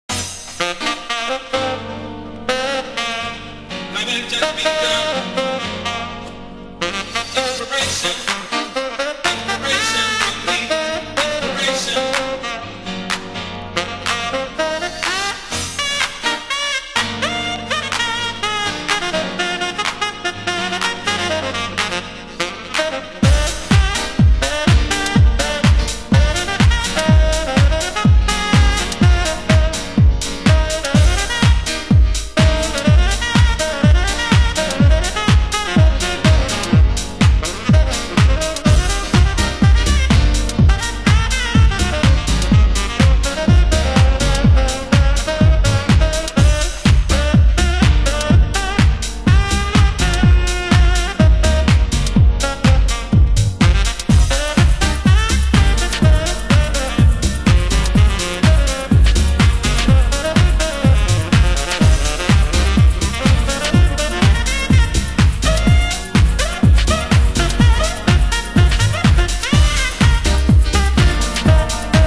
HOUSE MUSIC
盤質：少しチリパチノイズ有